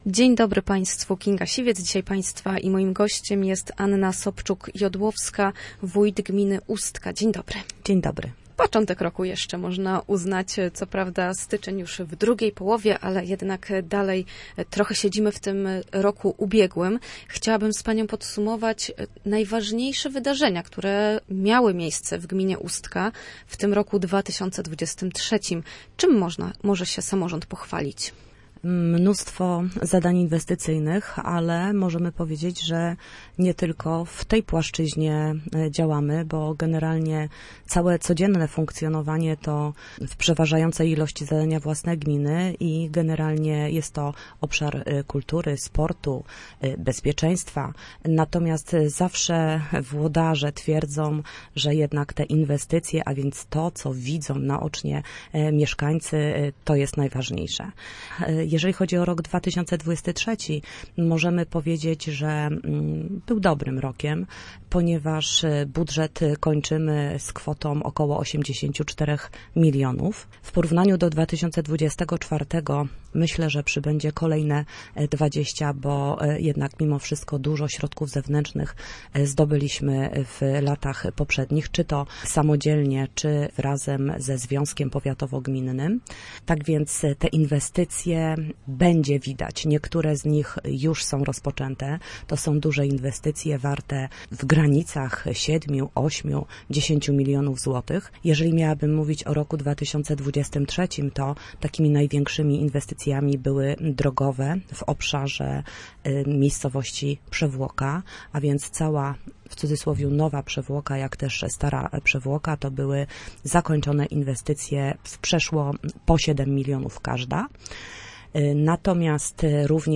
Gościem Studia Słupsk była Anna Sobczuk-Jodłowska, wójt gminy Ustka.